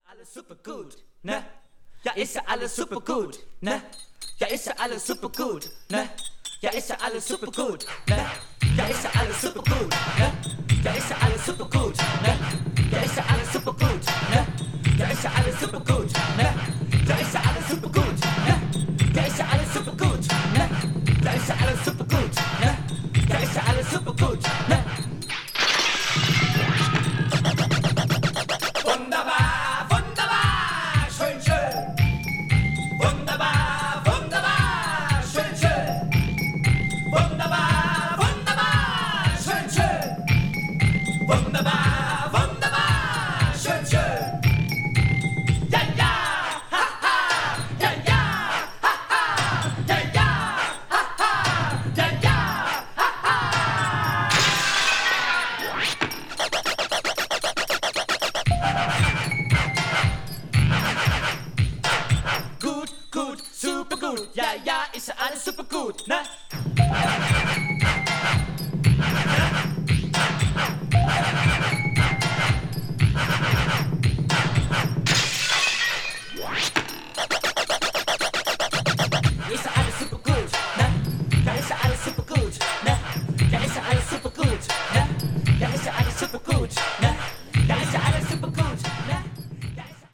body music   electronic   industrial   leftfield   new wave